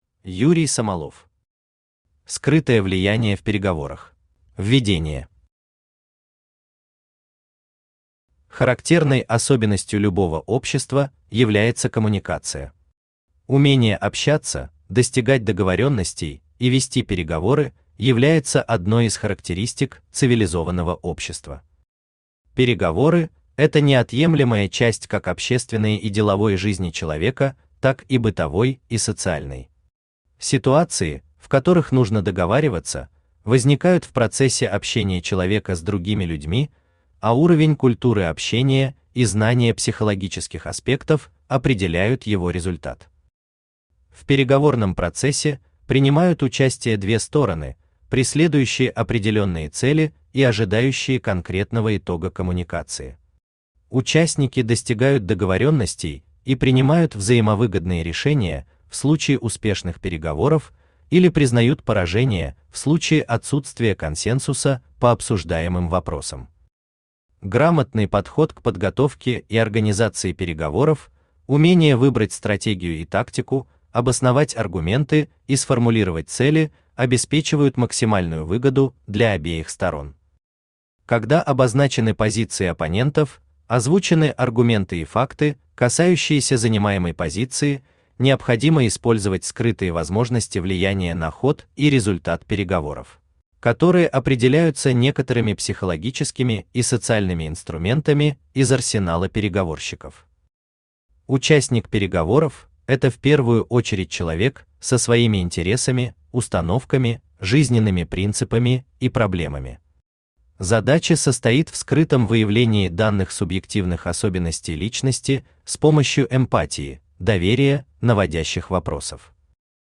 Аудиокнига Скрытое влияние в переговорах | Библиотека аудиокниг
Aудиокнига Скрытое влияние в переговорах Автор Юрий Самолов Читает аудиокнигу Авточтец ЛитРес.